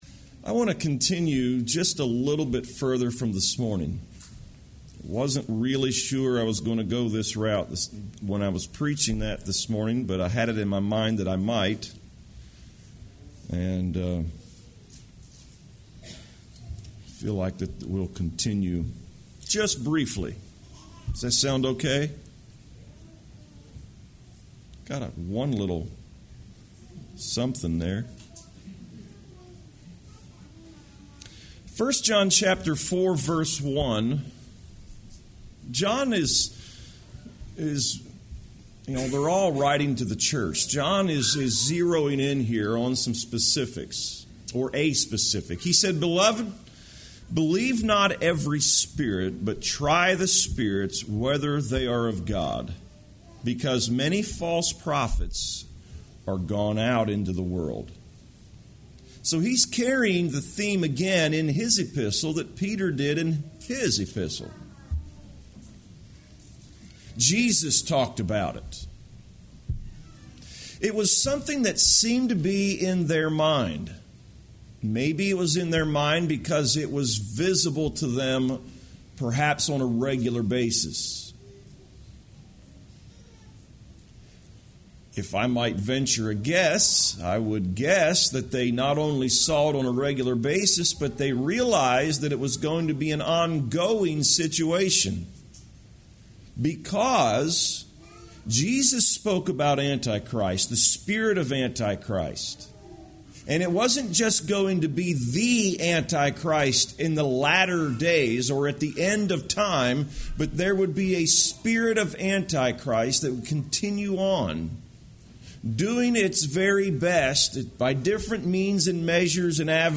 Discernment